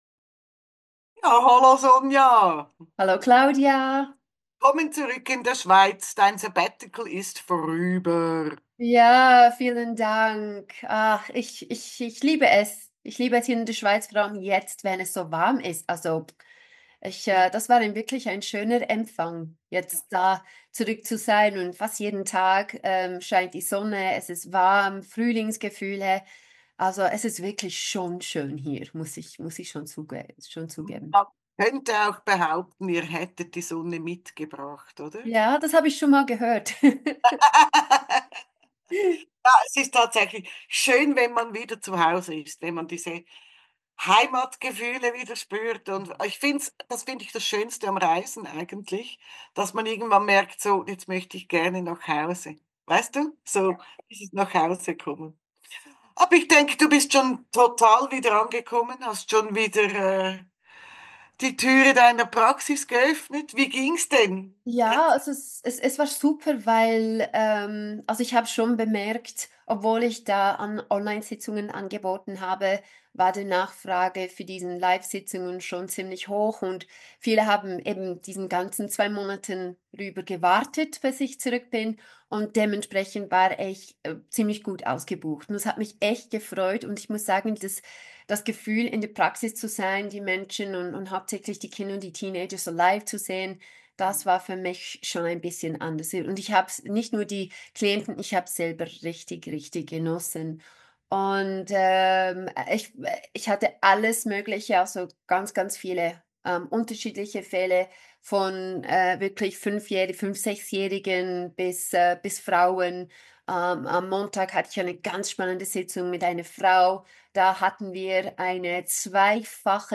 Der spontane Wochentalk